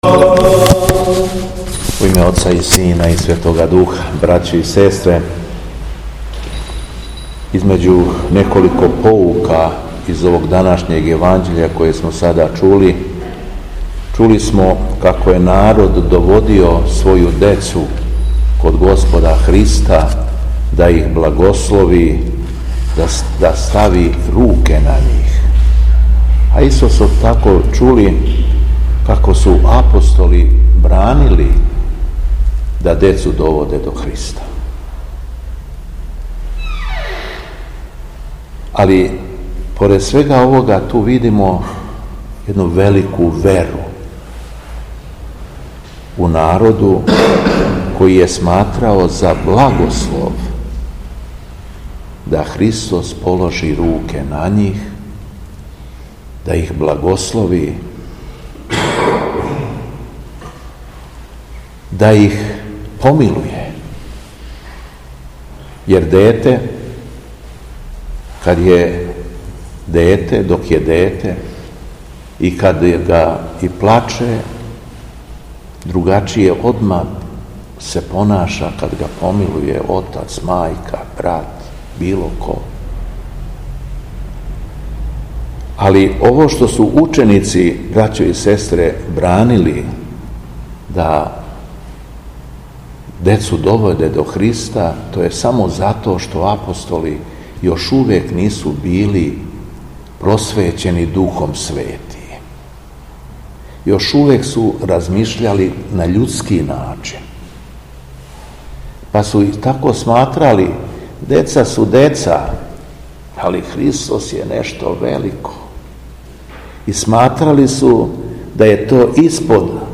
Беседа Његовог Високопреосвештенства Митрополита шумадијског г. Јована
Након прочитаног зачала из Светог Јеванђеља, Високопреосвећени митрополит се обратио сабраном верном народу поучним речима: